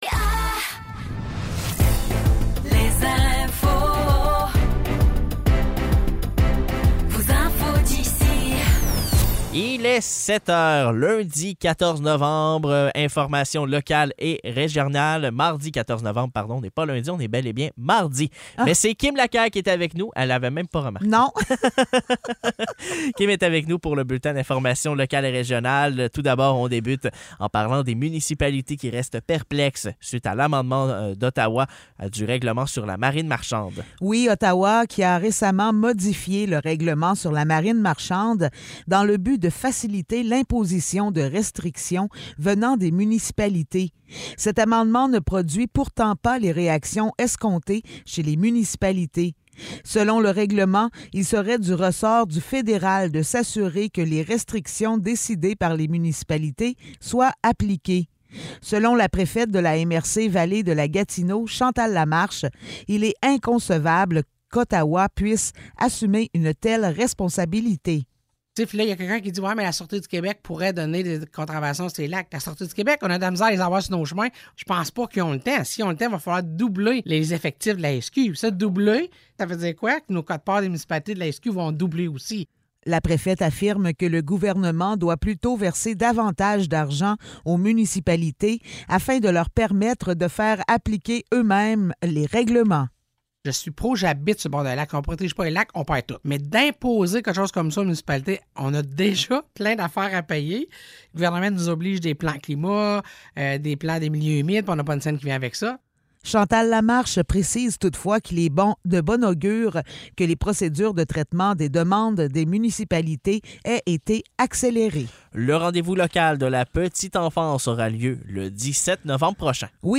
Nouvelles locales - 14 novembre 2023 - 7 h